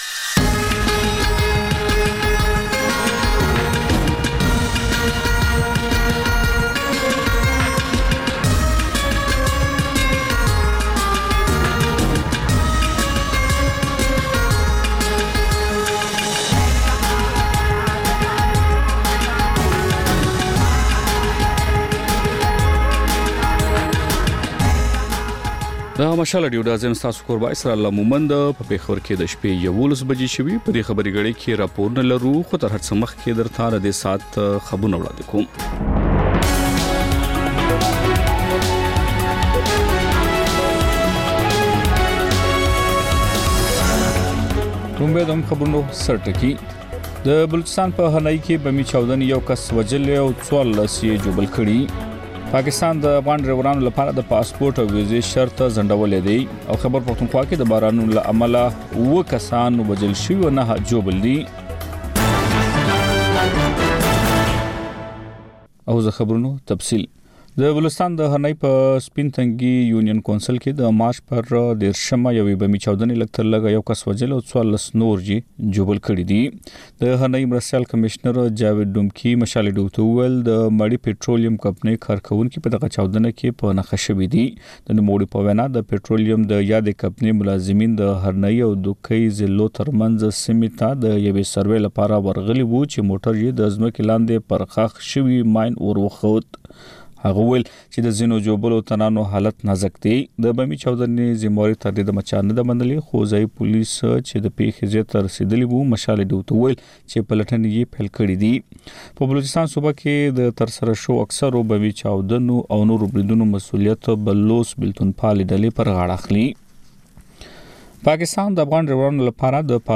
دلته د مشال راډیو د ۱۴ ساعته خپرونو دویمه او وروستۍ خبري ګړۍ تکرار اورئ. په دې خپرونه کې تر خبرونو وروسته بېلا بېل سیمه ییز او نړیوال رپورټونه، شننې، مرکې، رسنیو ته کتنې، کلتوري او ټولنیز رپورټونه خپرېږي.